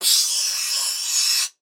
science_fiction_air_burst_pneumatic_hose_discharge
Tags: Sci Fi Play